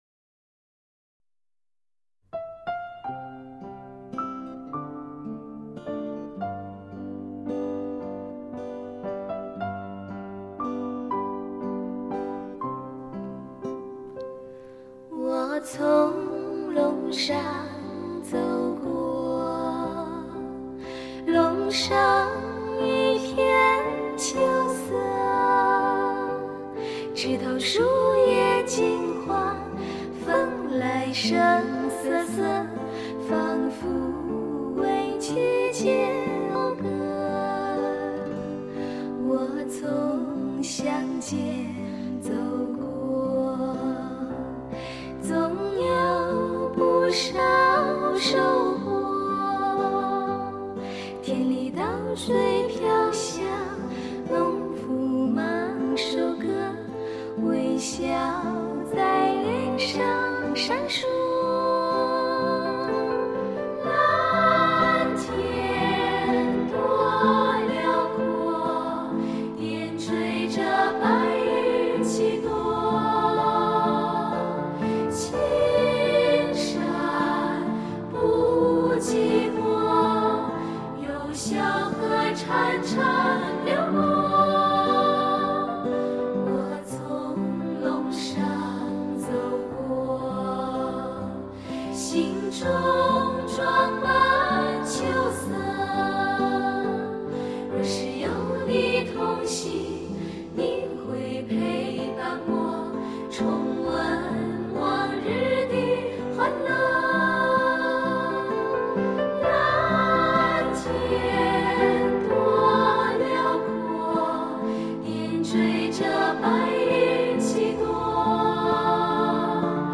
专辑强调精准的录音水准， 所以音色的纯净是其最终的追求，没有半点含糊拖拉， 人声格外清爽，